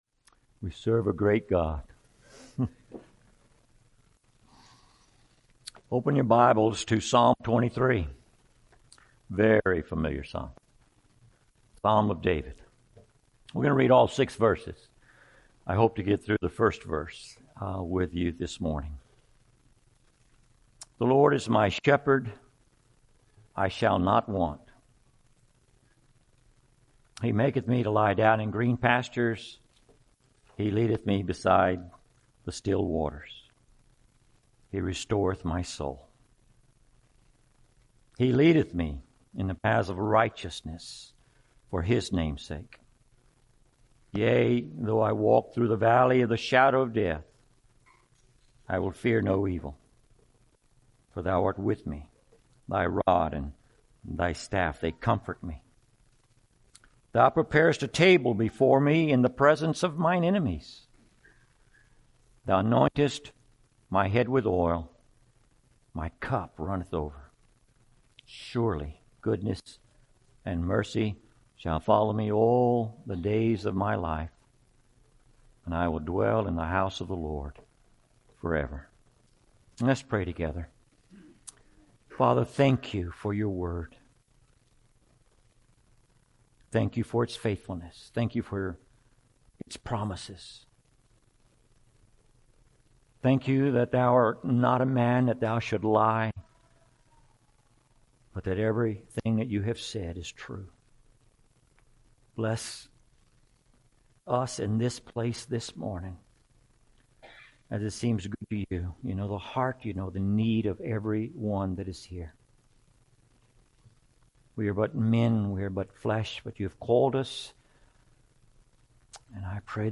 2022 Category: Full Sermons Christian